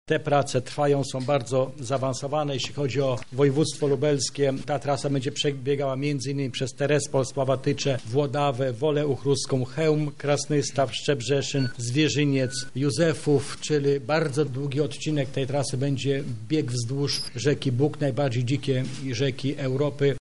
Ta inwestycja na pewno poprawi atrakcyjność turystyczną naszego województwa – mówi Krzysztof Grabczuk wicemarszałek województwa lubelskiego